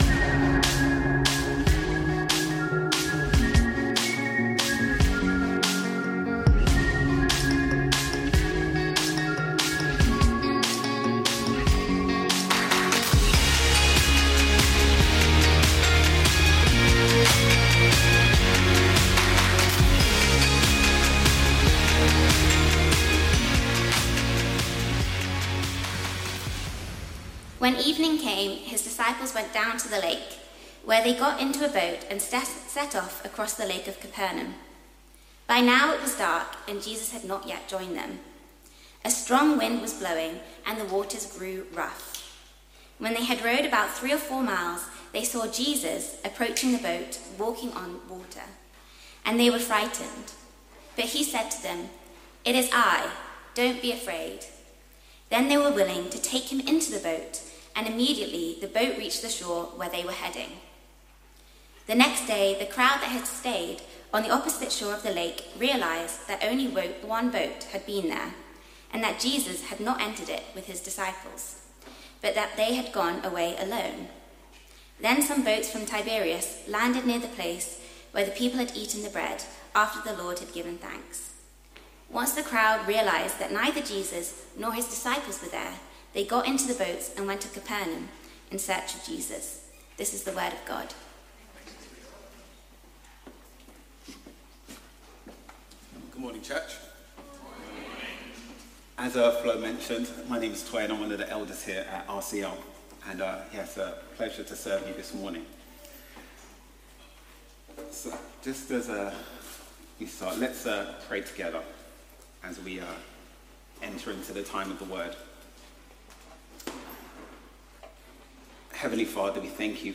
Sunday Sermons - Reality Church London